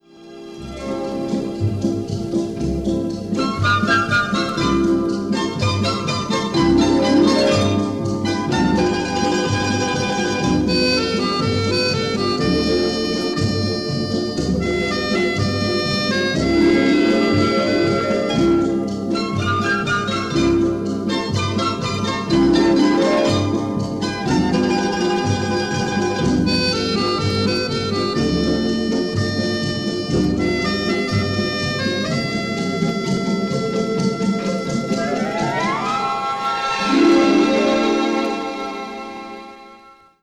lavish and splendid work
written for a large orchestra
a true and fascinating symphonic poem